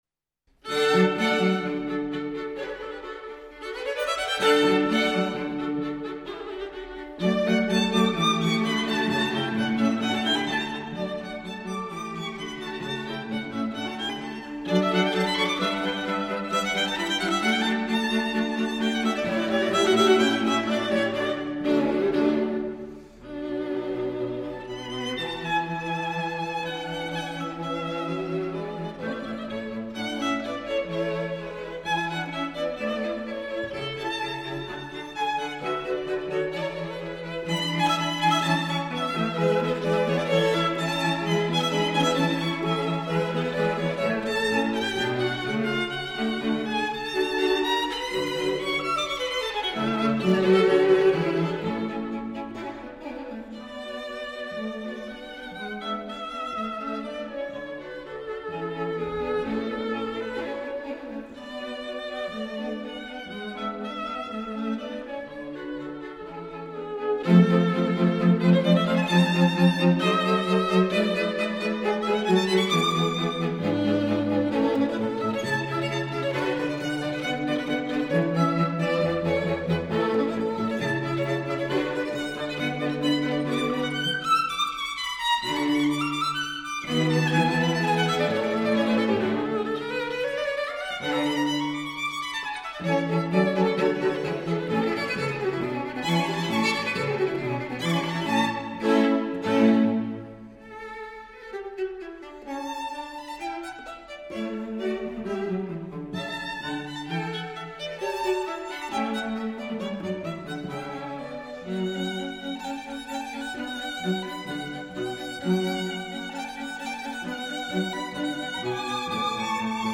String Quartet in D major
Allegro